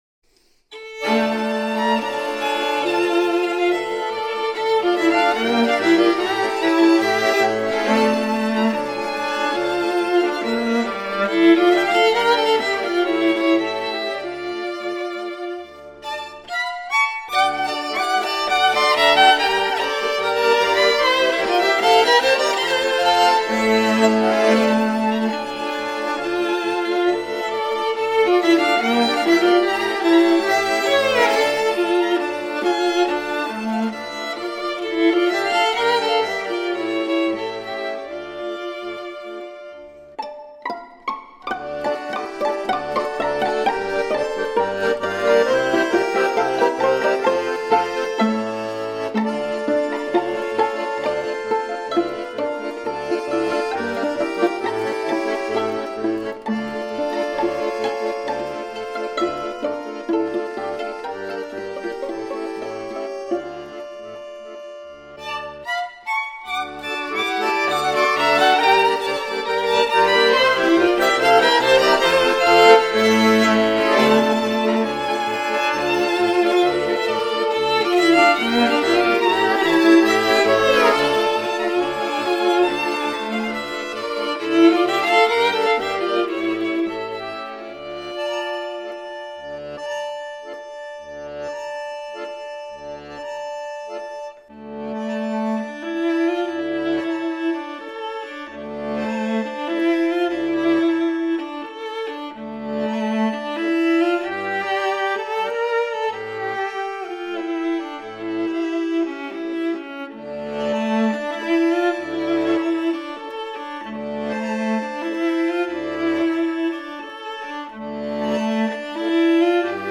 violon, voix
alto, voix, guitare
accordéon, voix, darbuka